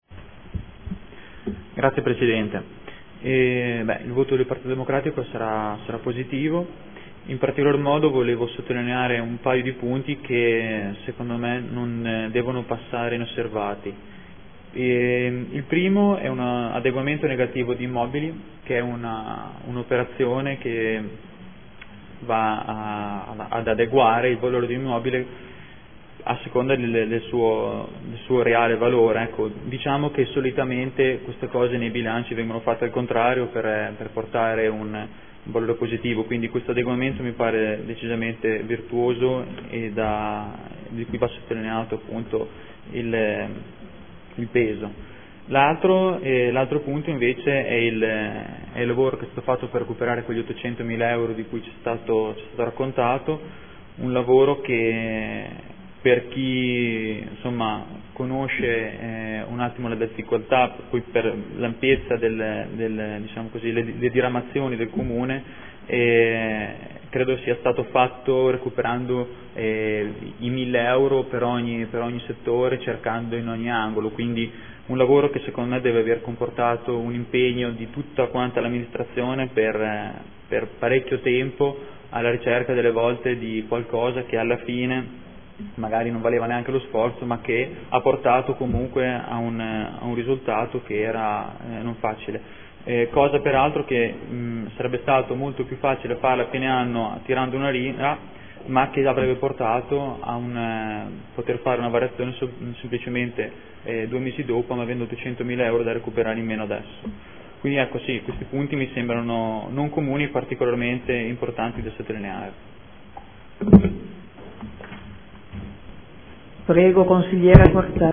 Diego Lenzini — Sito Audio Consiglio Comunale
Seduta del 27/11/2014 Dichiarazione di voto.